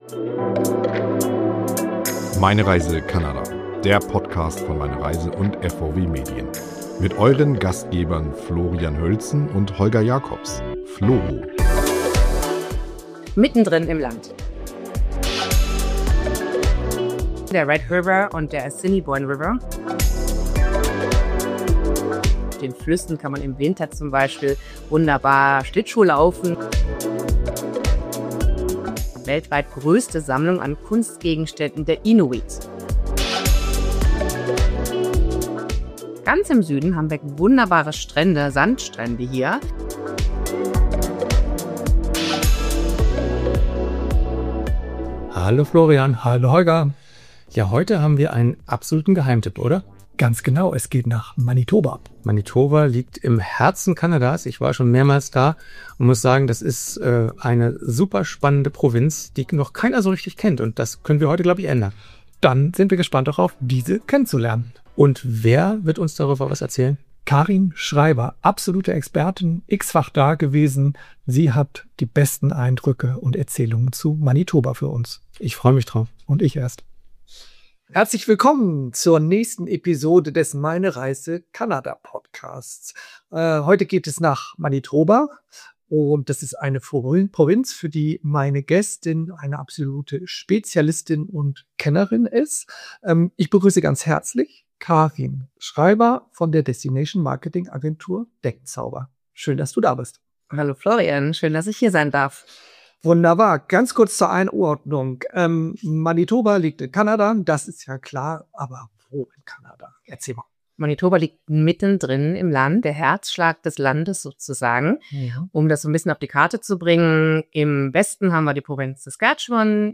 Die Manitoba-Kennerin hat auch ein paar tierische O-Töne mitgebracht.